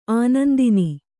♪ ānandini